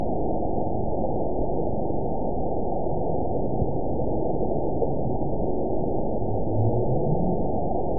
event 912445 date 03/26/22 time 22:57:35 GMT (3 years, 1 month ago) score 9.29 location TSS-AB01 detected by nrw target species NRW annotations +NRW Spectrogram: Frequency (kHz) vs. Time (s) audio not available .wav